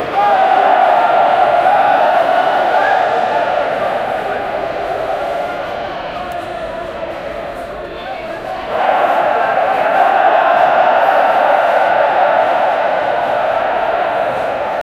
the-roar-of-the-crowd-gl2b5jof.wav